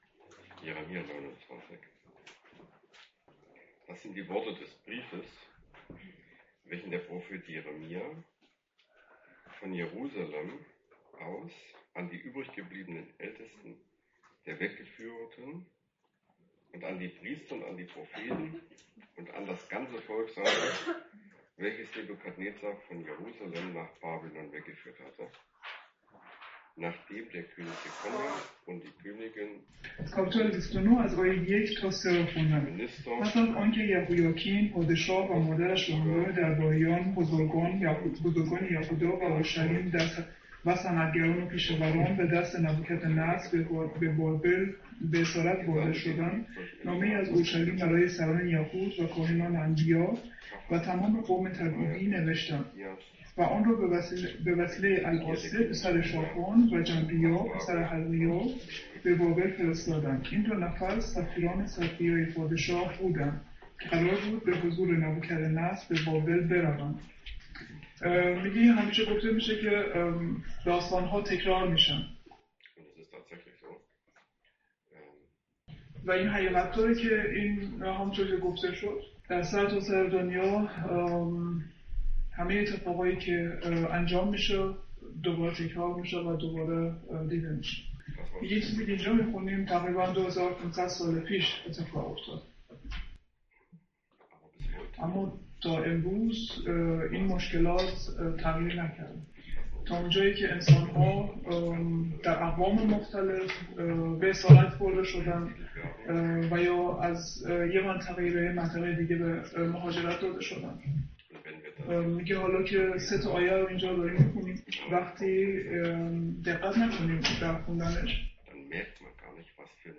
ارمیا 29، 1 تا 14 | Predigt in Farsi - Europäische Missionsgemeinschaft